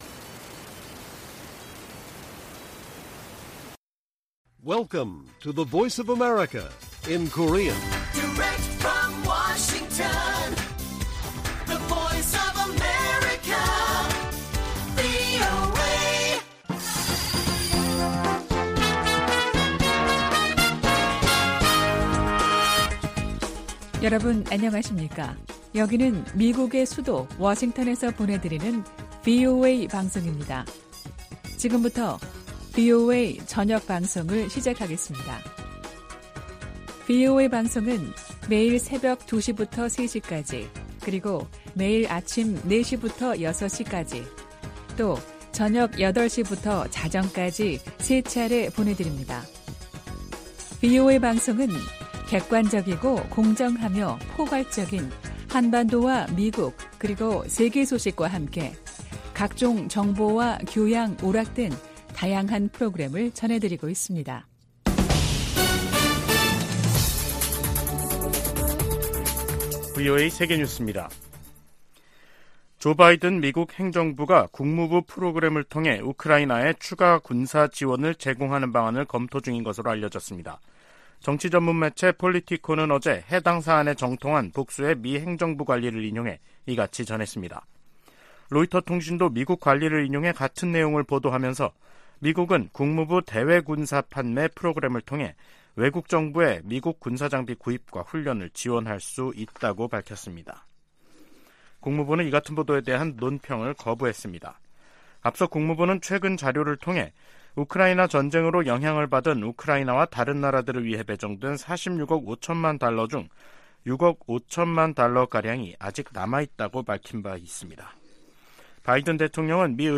VOA 한국어 간판 뉴스 프로그램 '뉴스 투데이', 2023년 10월 6일 1부 방송입니다. 미 국방부는 최근 공개한 대량살상무기(WMD) 대응 전략을 정치·군사적 도발로 규정한 북한의 반발을 일축했습니다. 미국의 인도태평양 지역 동맹과 파트너십이 그 어느 때보다 강화되고 있으며, 미국의 가장 큰 전략적 이점 중 하나라고 미국 국방차관보가 말했습니다. 미 상원의원들이 올해 첫 한반도 안보 청문회에서 대북 정책을 실패로 규정하며 변화 필요성을 강조했습니다.